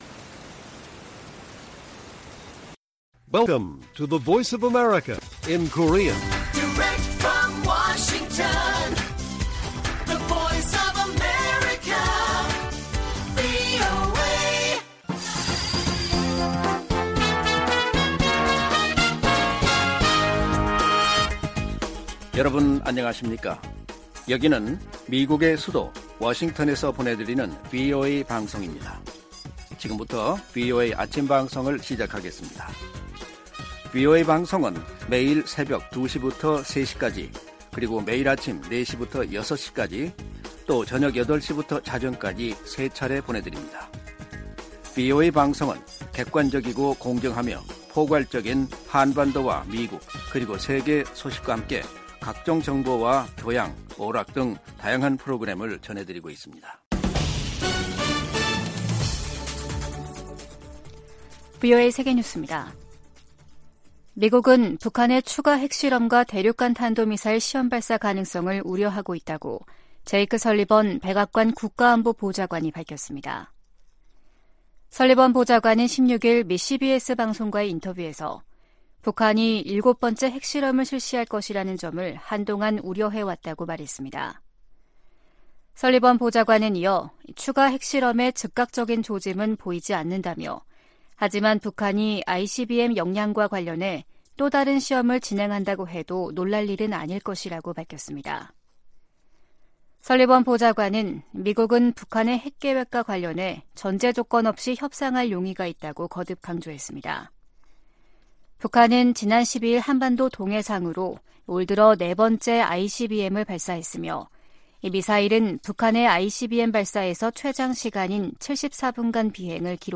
세계 뉴스와 함께 미국의 모든 것을 소개하는 '생방송 여기는 워싱턴입니다', 2023년 7월 18일 아침 방송입니다. '지구촌 오늘'에서는 러시아가 흑해 곡물 협정 종료를 발표한 소식 전해드리고, '아메리카 나우'에서는 공화당 강경파의 일부 주장을 관철해 하원을 통과한 국방수권법안(NDAA)이 대통령 책상에 오르는 일은 없을 것이라고 제이크 설리번 국가안보보좌관이 단언한 이야기 살펴보겠습니다.